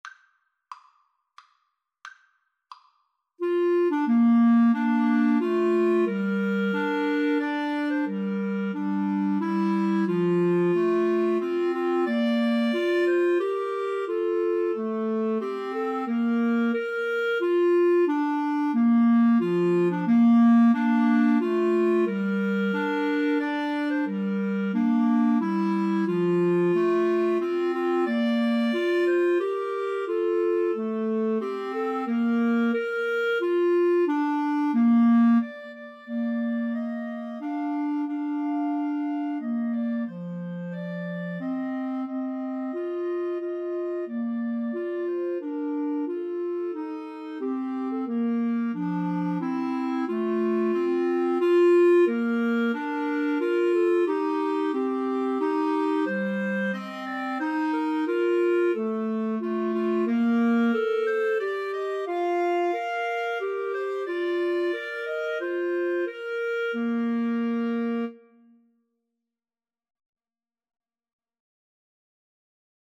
Bb major (Sounding Pitch) C major (Clarinet in Bb) (View more Bb major Music for Clarinet Trio )
Maestoso = c.90
3/4 (View more 3/4 Music)
Clarinet Trio  (View more Intermediate Clarinet Trio Music)